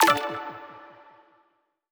button-direct-select.wav